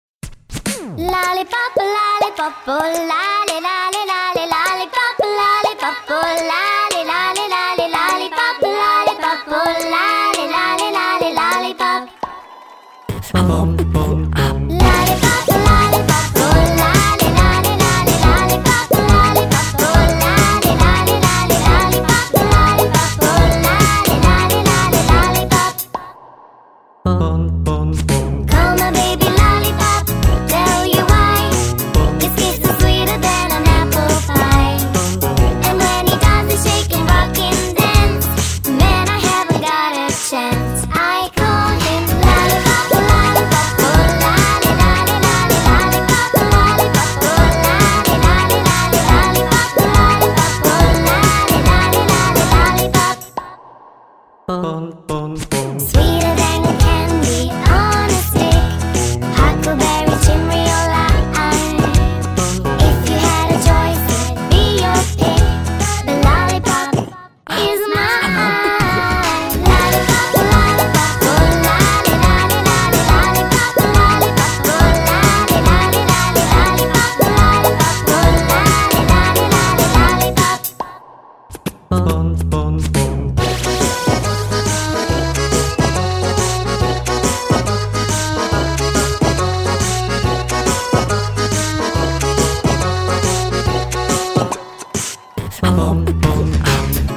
• Категория: Детские мелодии